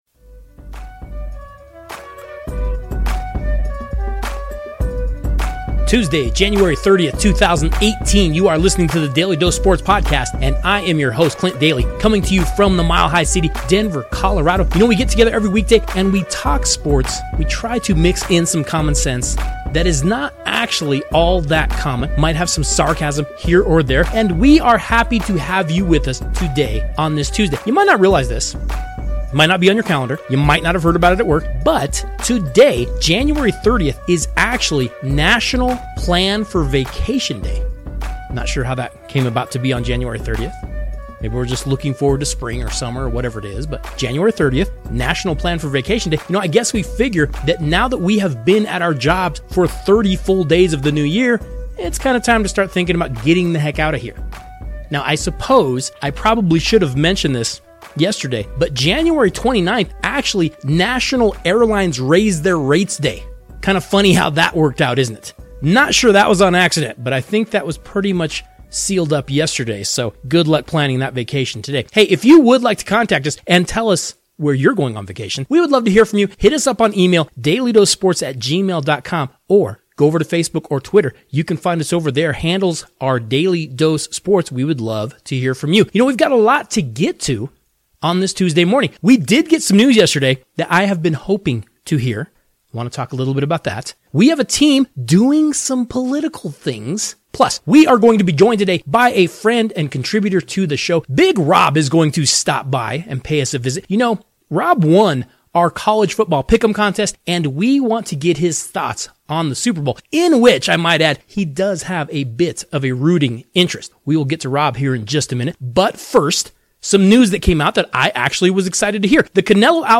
Then, a friend and contributor to the show stops by to discuss relegation in soccer, burning couches, and Super Bowl 52.